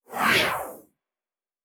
Sci-Fi Sounds
Synth Whoosh 1_1.wav